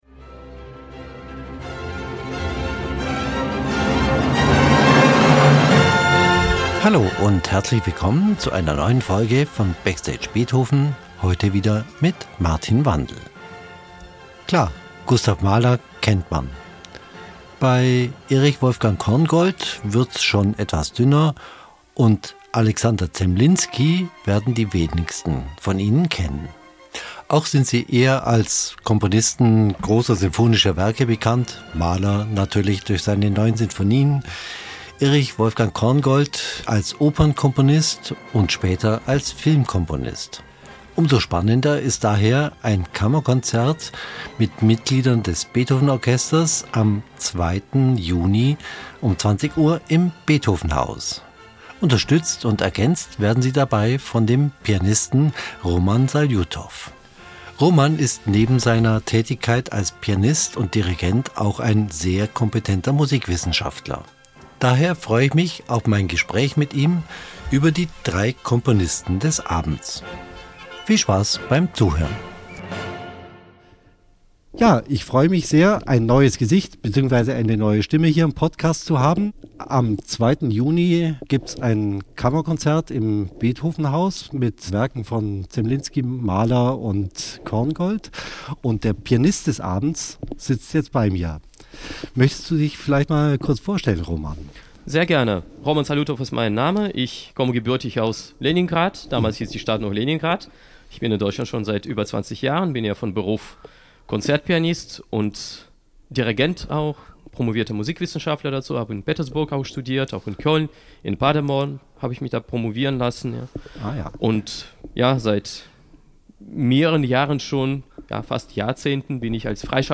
Neuigkeiten und Talk rund um Beethoven, Musik und Orchesterleben